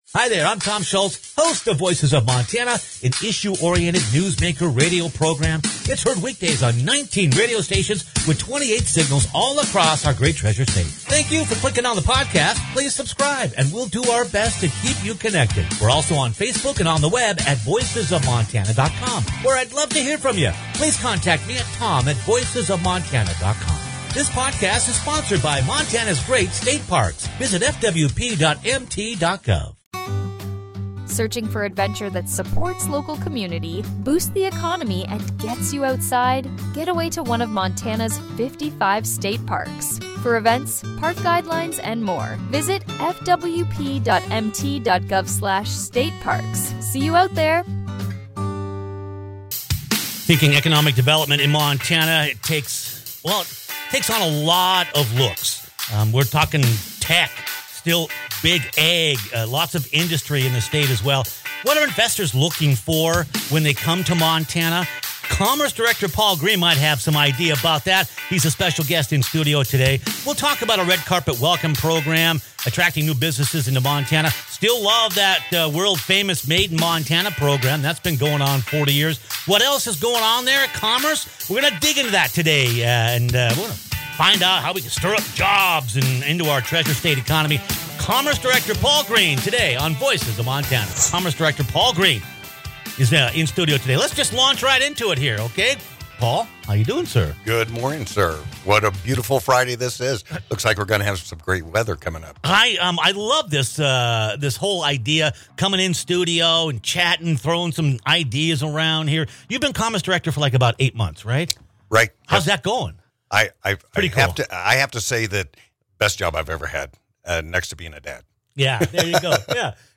Commerce Director Paul Green pays a visit in-studio! There was a lot to cover, from attracting new businesses to Montana, encouraging entrepreneurship, main street business support, the shifting economy ... and some Texas versus Montana 6-man football.